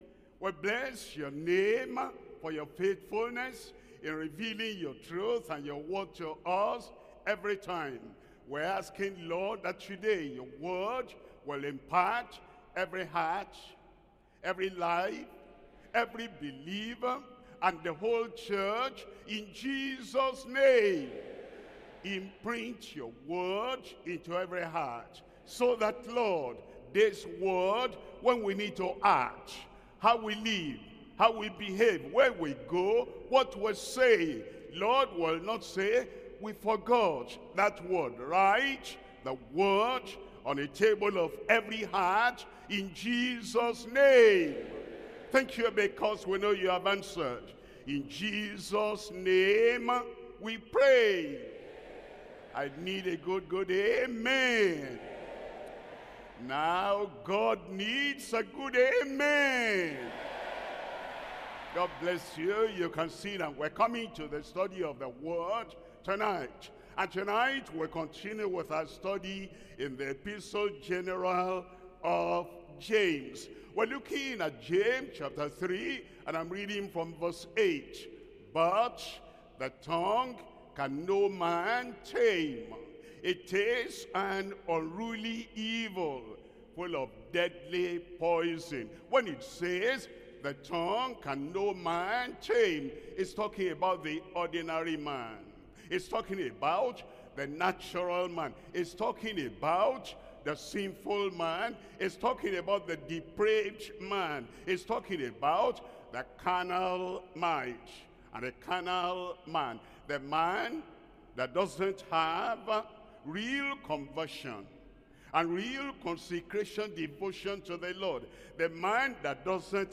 Sermons - Deeper Christian Life Ministry
Bible Study